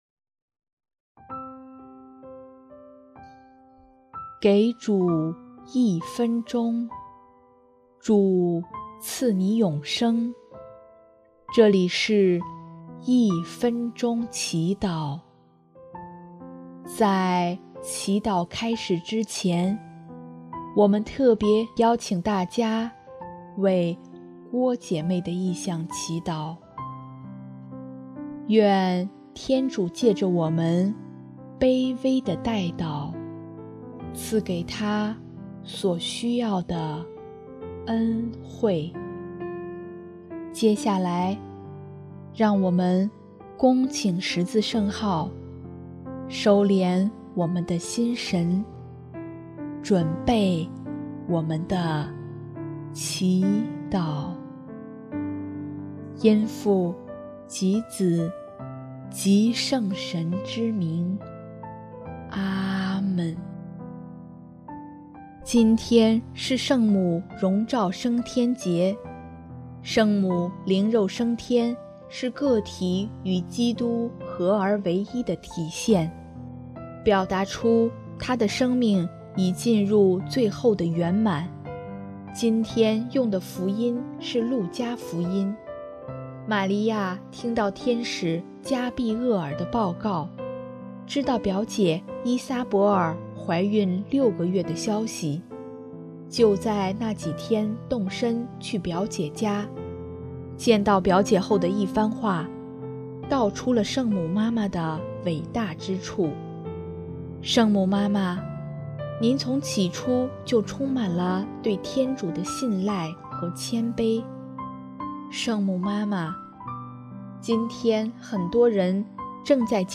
【一分钟祈祷】|8月15日 圣母妈妈在天为人类的得救代祷吧